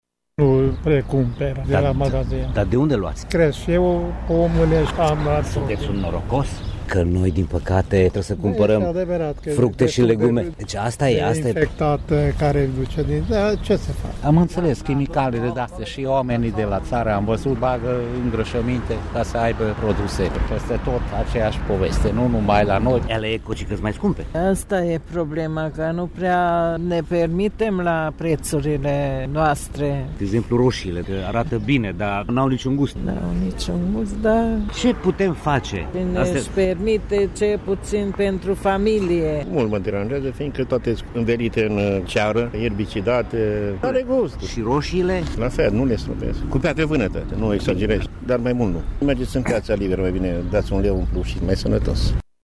Târgumureșenii sunt conștienți că puține fructe sau legume mai sunt bio, cei mai mulți agricultori folosesc pesticide pentru a obține recolte mari și profit: